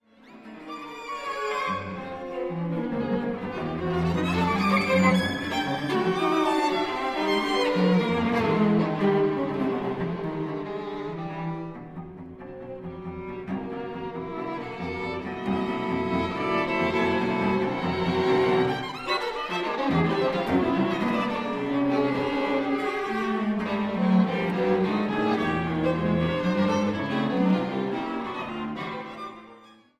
00s Classical Music CDs